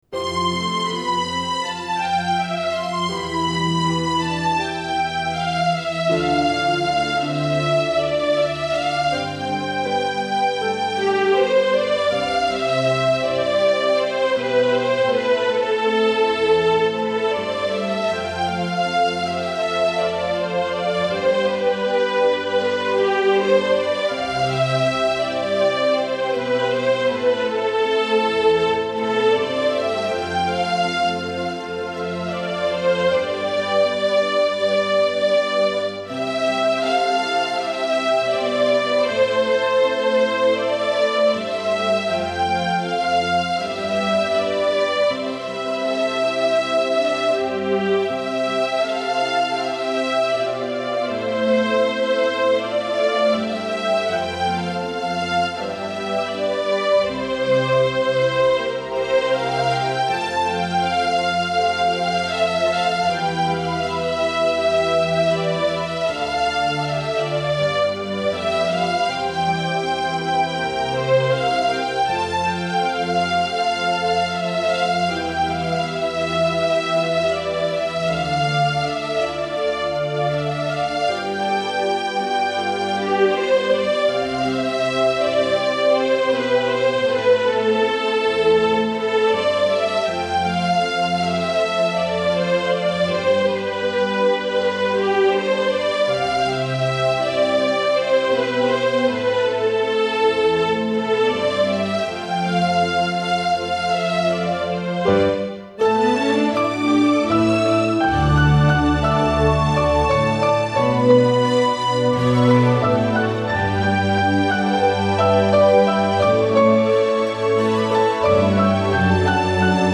デモテープ、メモテープ、練習です。
演奏したものを多重録音（テープレコーダで何回も重ね録音する）したものです。
音質は悪いです。（笑）
演劇の曲は、出来るだけ簡単で覚え安いメロディーを何度も繰り返す手法が
または、メロディが耳に残るように作ったつもりです。